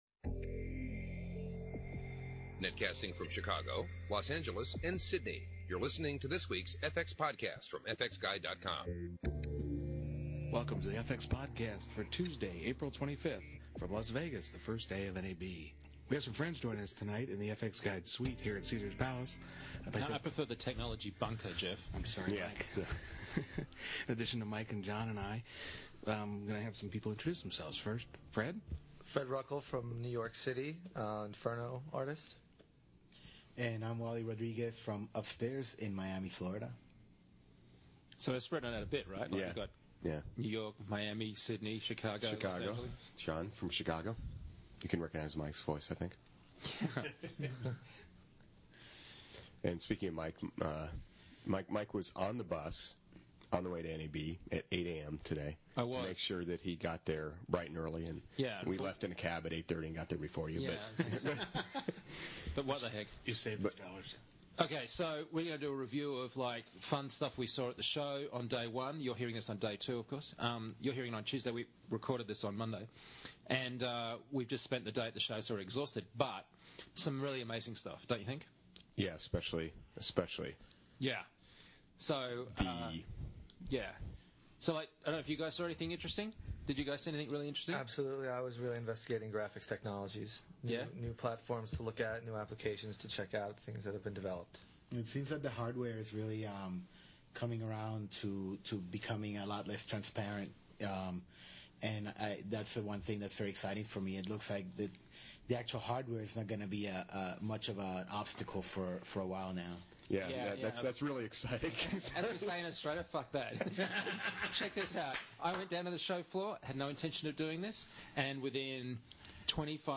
Roundtable and interviews covering: The Red Camera, Apple developments and SDK DVD produced by fxphd, Plug-ins, Filmlight, Gum...